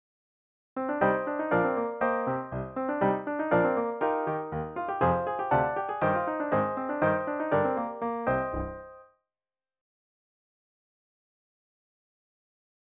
Easy Piano and Voice